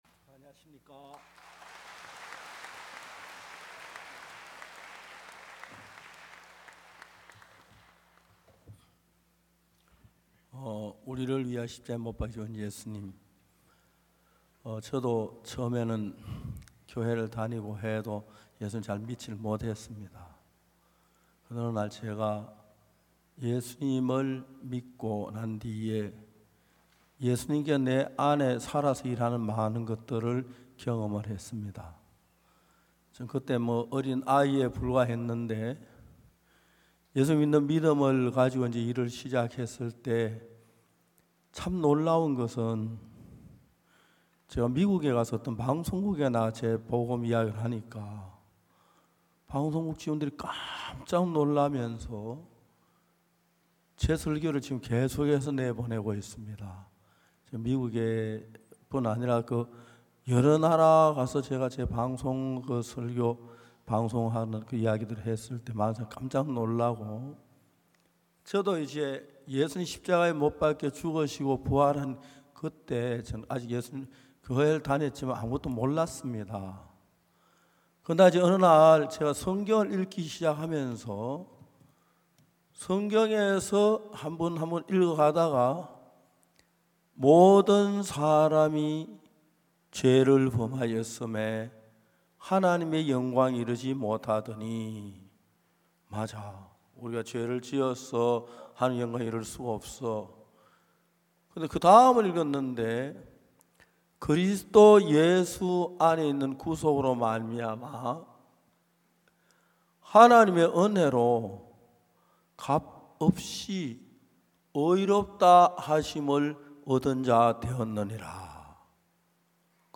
설교를 굿뉴스티비를 통해 보실 수 있습니다.
2025 전반기 인천성경세미나